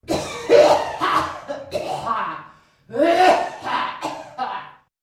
Звуки рвоты, тошноты
Звук старческого кашля при тошноте